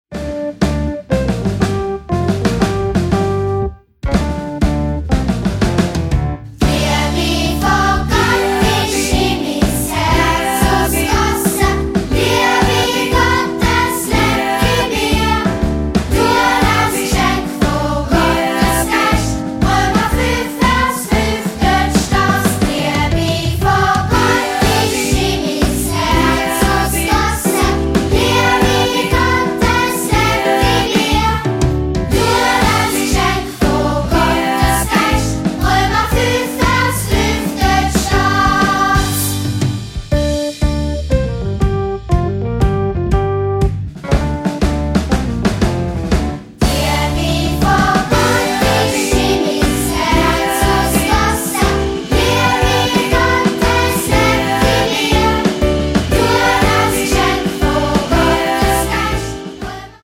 20 Bibelverssongs
24 Bibelverse peppig und eingängig vertont